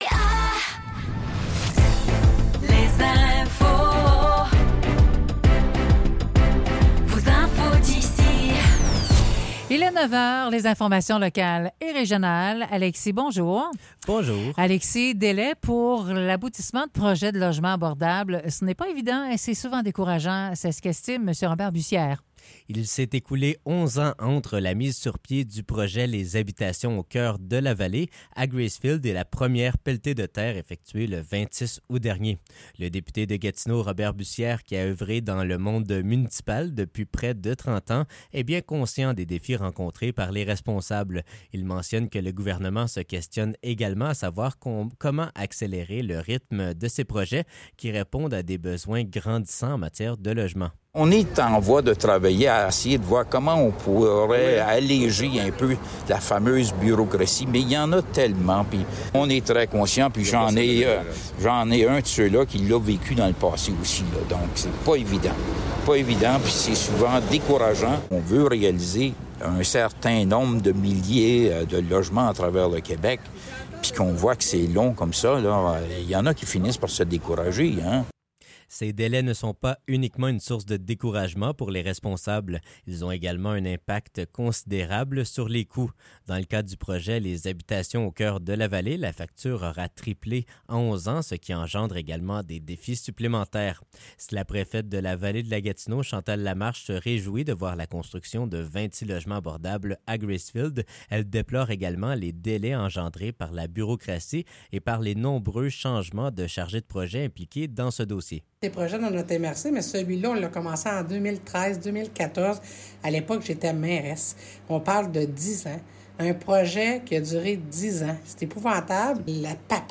Nouvelles locales - 29 Août 2024 - 9 h